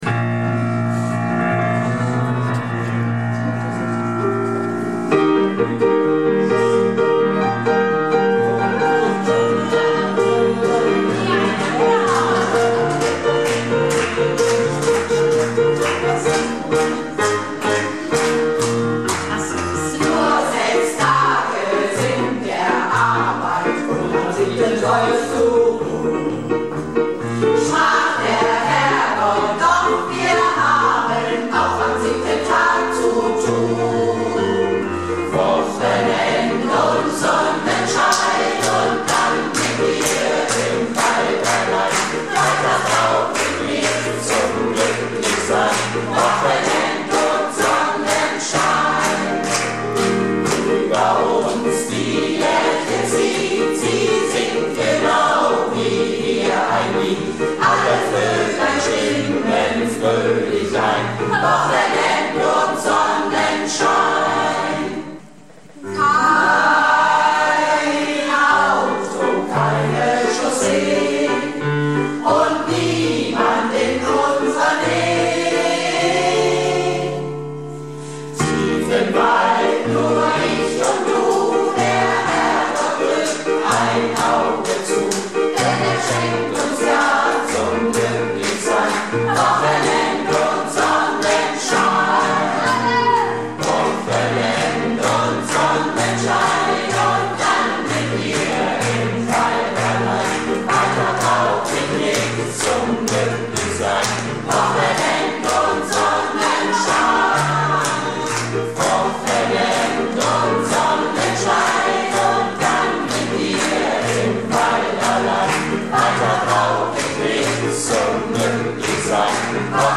Malle Diven - Konzert in Dornum am 22.06.19
Wochenend und Sonnenschein (Live in Dornum